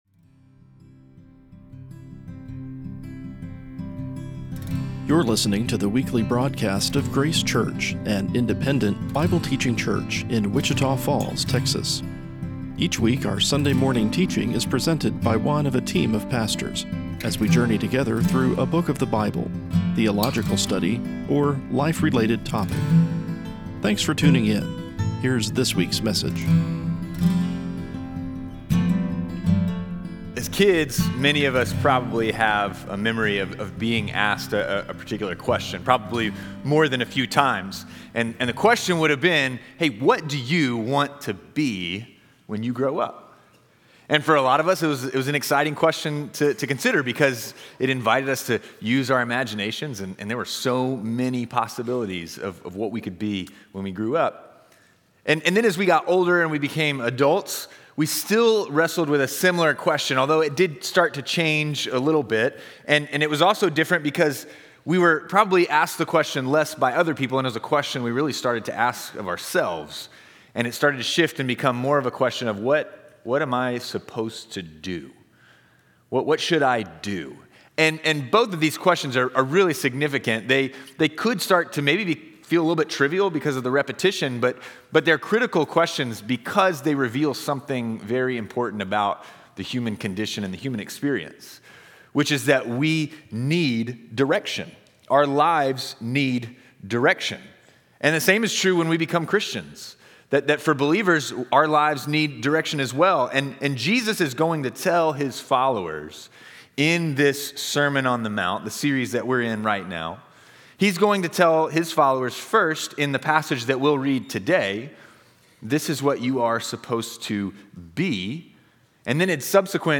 A weekly podcast of Sunday morning Bible teaching from Grace Church in Wichita Falls, Texas.